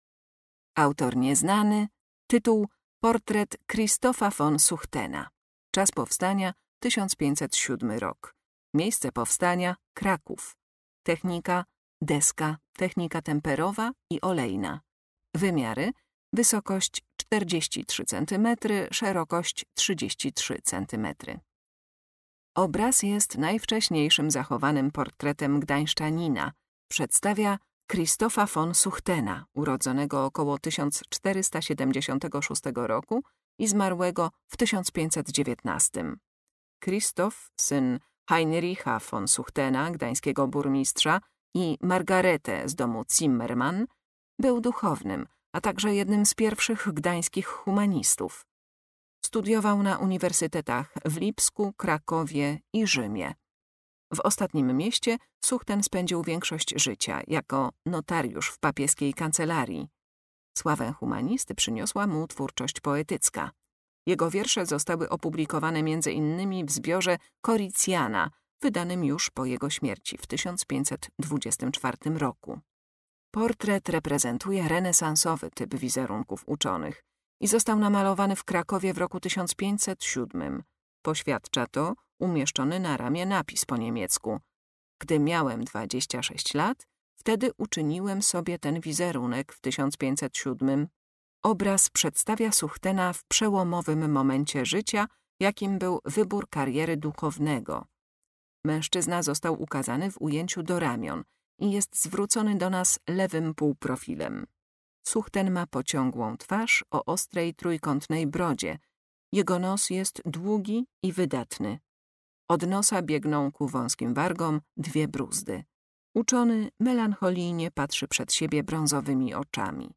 Audiodeskrypcje do wystawy stałej w Oddziale Sztuki Dawnej